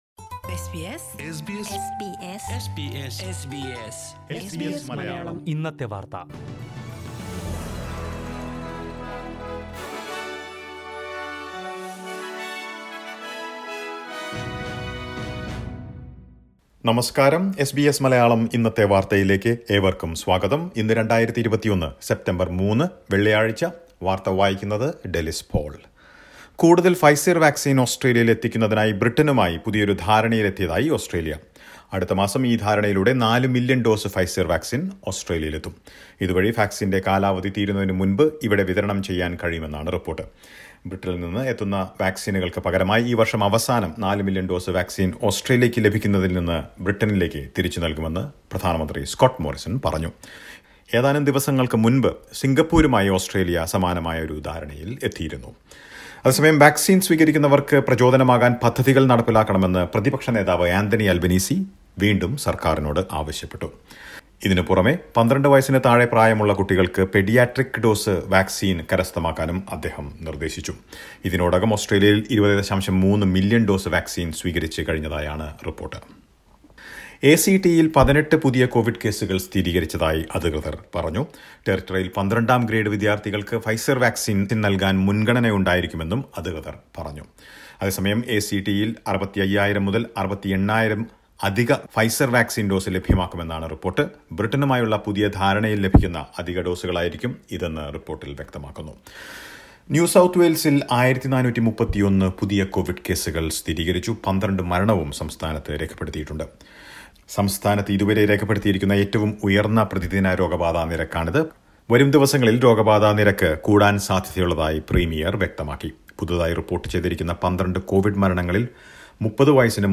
news_bulletin_309_0.mp3